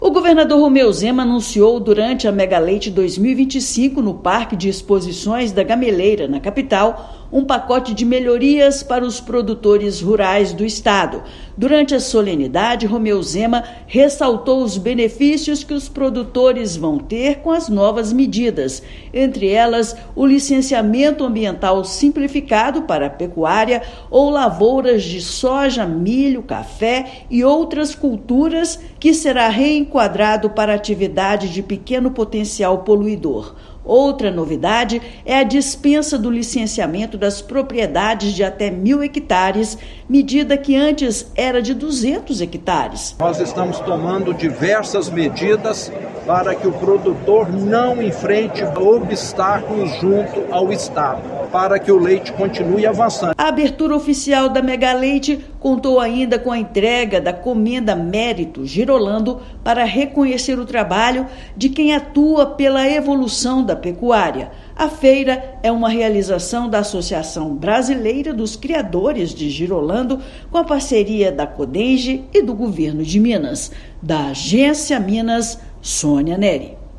Novidades como o aprimoramento do licenciamento ambiental e de propriedade foram destacadas no Megaleite 2025. Ouça matéria de rádio.